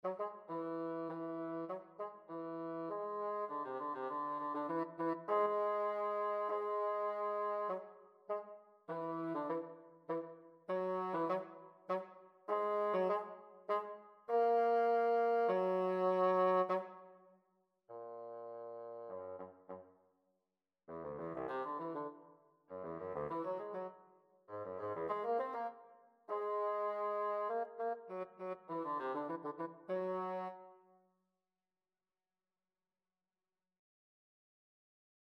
The task for this project was to compose nine pieces for woodwind instruments for nine different scales.
Whole-tone scale a
bassoon-audio.mp3